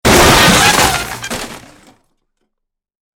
Car Collision Sound Effect
A high-quality recording of a violent vehicle collision. This sound effect captures the initial metallic impact followed by the distinct sound of breaking glass and falling debris.
Car-collision-sound-effect.mp3